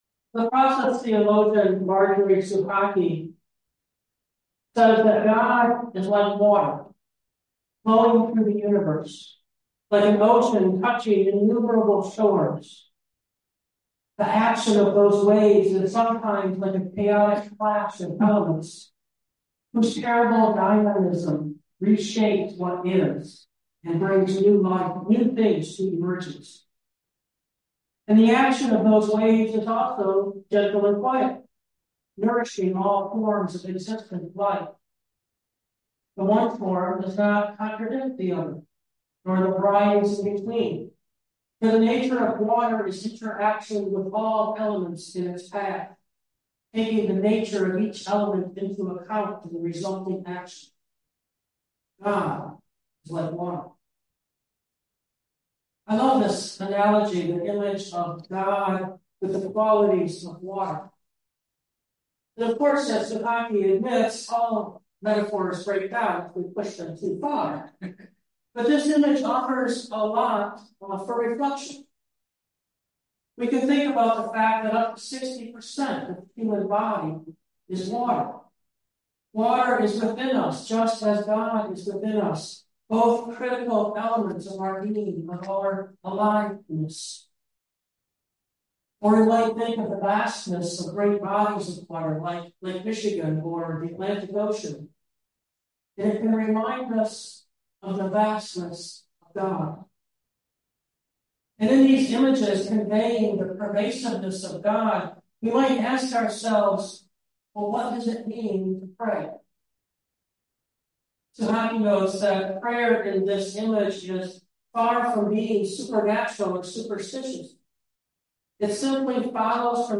Note: We apologize for the poor sound quality of this week’s recording.